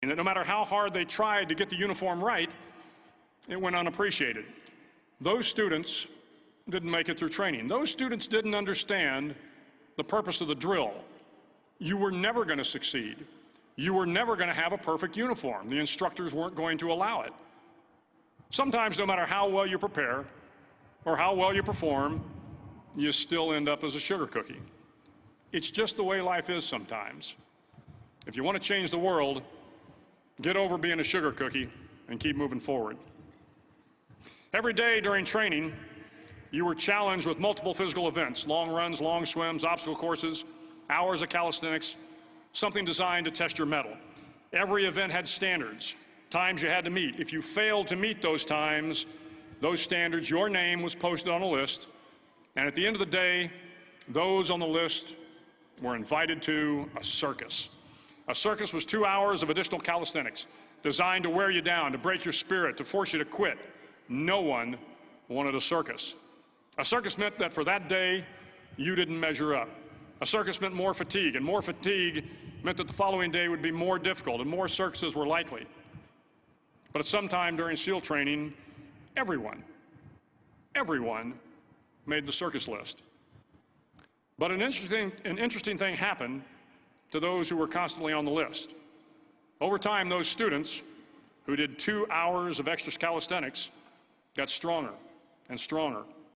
公众人物毕业演讲 第235期:威廉麦克雷文2014德州大学演讲(7) 听力文件下载—在线英语听力室